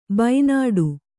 ♪ baināḍu